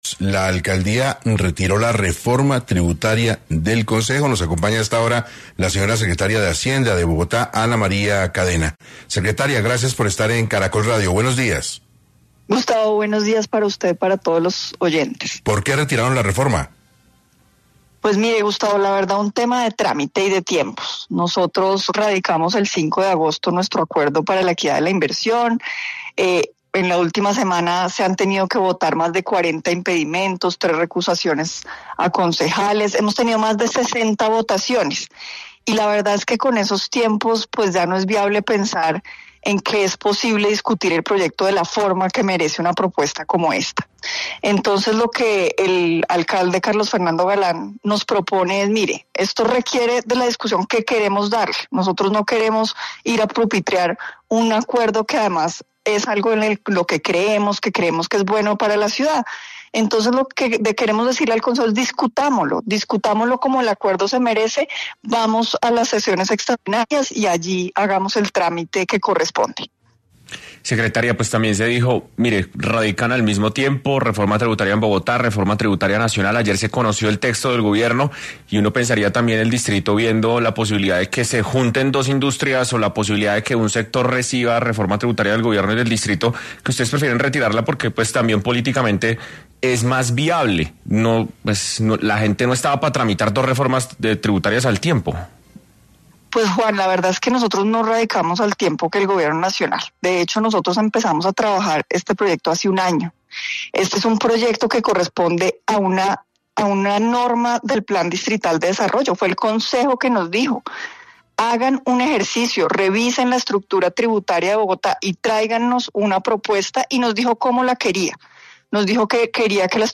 Ana María Cadena, secretaria de Hacienda de Bogotá, habló en 6AM sobre el retiro del Proyecto de Acuerdo 767 que planteaba una reforma tributaria en la capital del país.